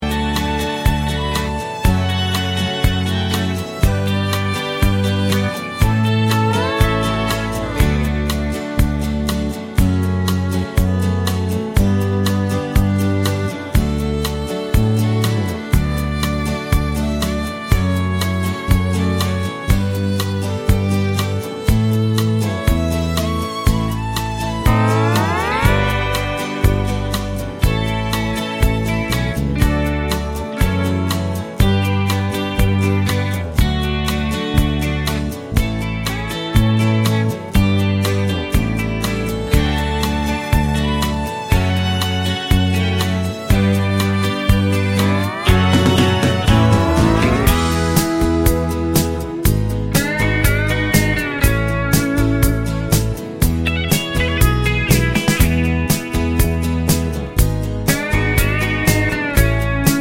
no Backing Vocals Country (Male) 2:45 Buy £1.50